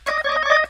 yes lord Meme Sound Effect
This sound is perfect for adding humor, surprise, or dramatic timing to your content.
yes lord.mp3